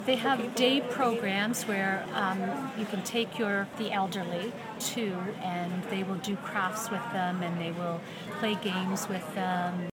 A volunteer with the local branch, says the programs help the elderly suffering form Alzheimer’s.